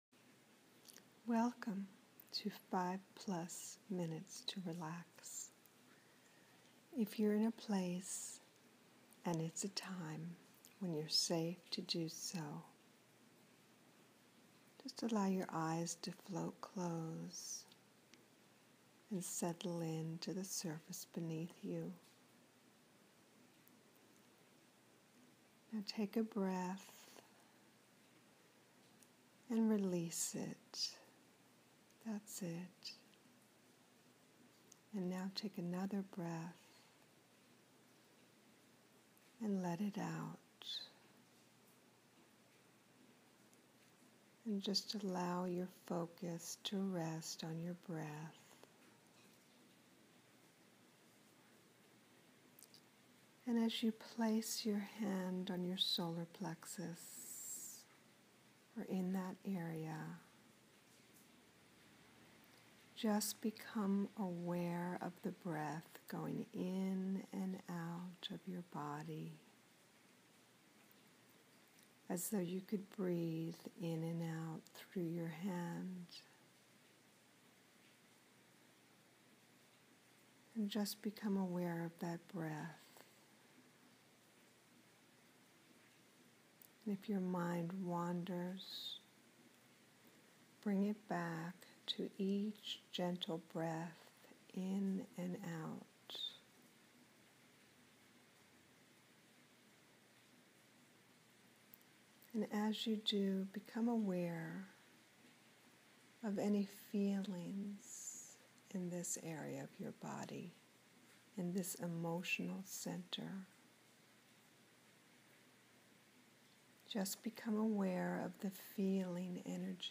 5Minutes-to-Relax-Feelings-Release.mp3